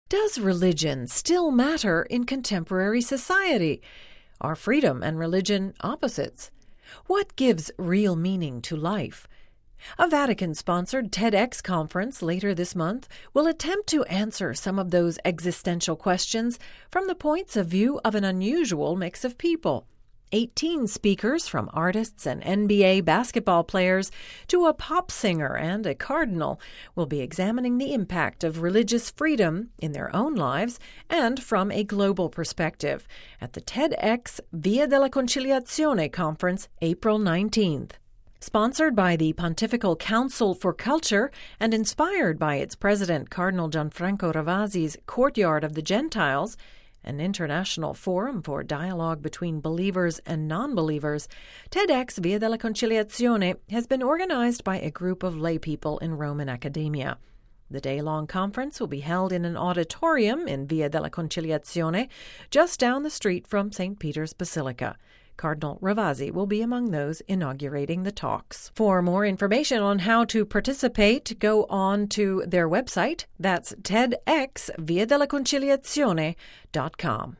brief report